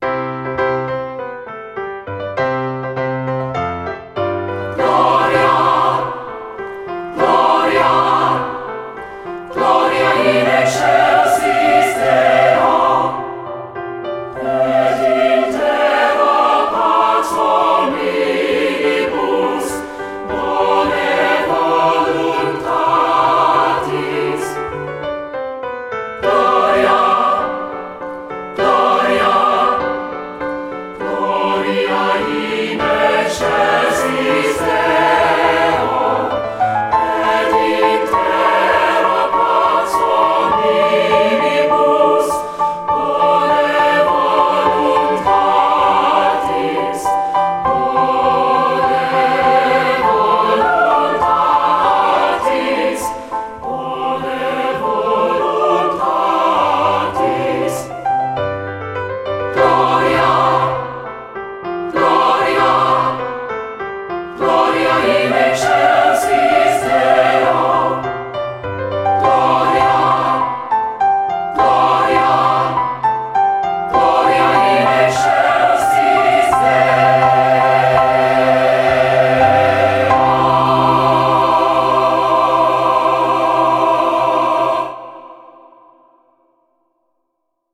secular choral
3-part mixed / SAB (SATB recording), sample